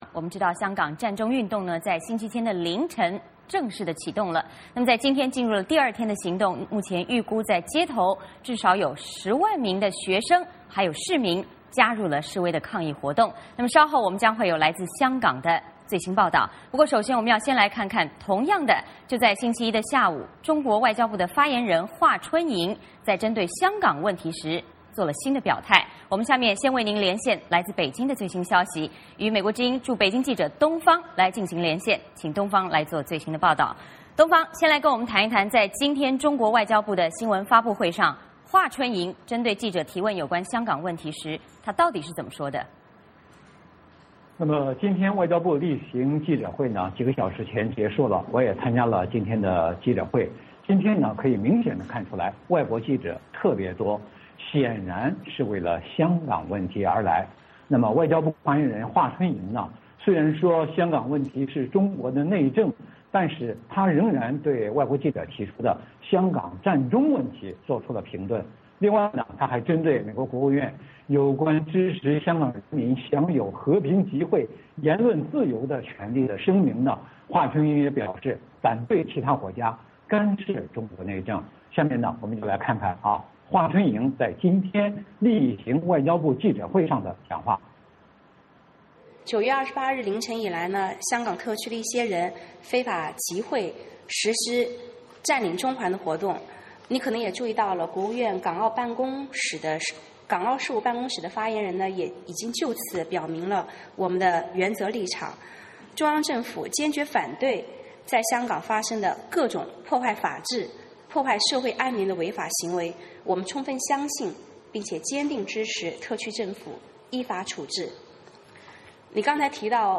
就在星期一的下午，中国外交部发言人华春莹针对香港问题作了新的表态。我们为您连线了来自北京的最新消息。